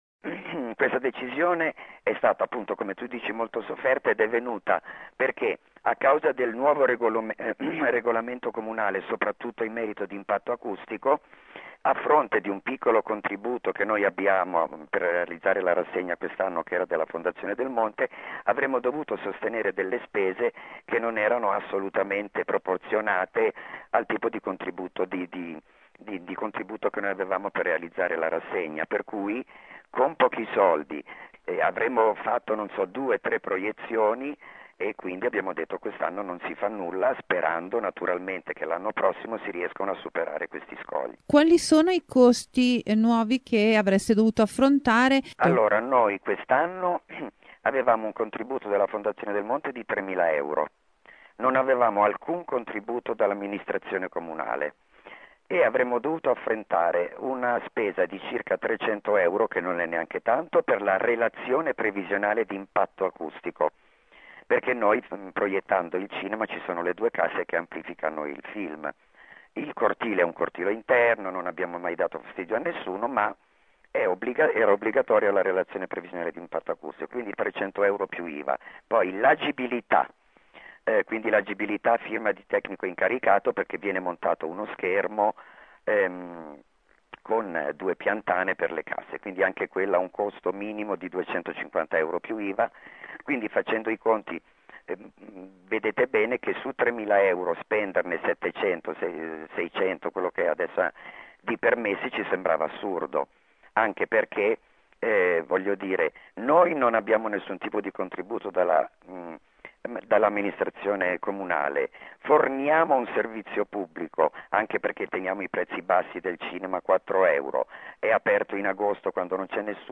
Abbiamo intervistato